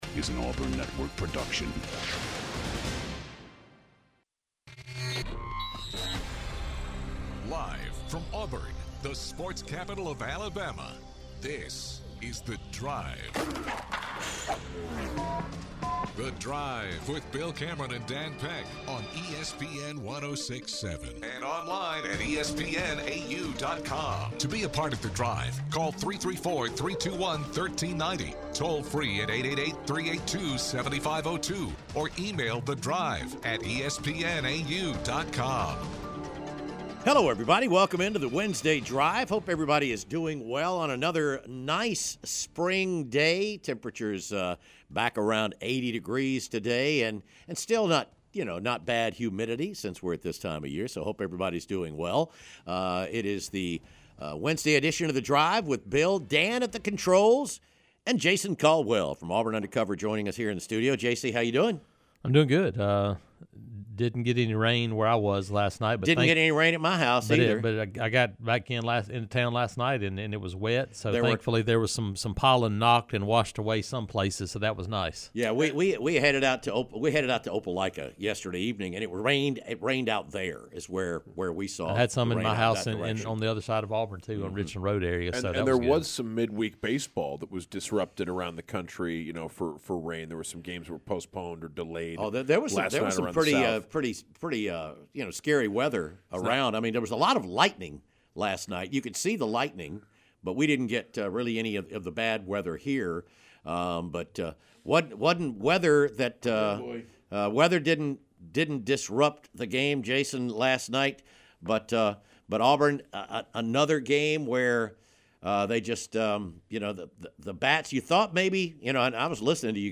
Former Auburn Head Coach Gene Chizik calls the show to share his thoughts about Alex Golesh, his views on the state of college football, and his memories of working with Gus Malzahn and coaching Kodi Burns during his tenure at Auburn.